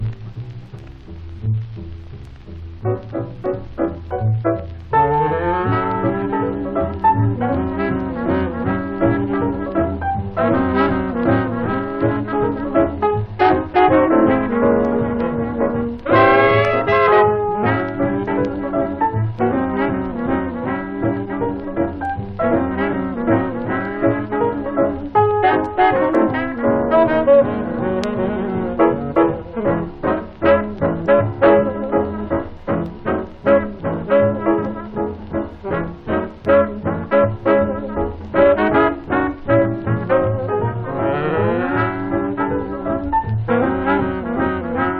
Jazz, Jump Blues, Swing　US　12inchレコード　33rpm　Mono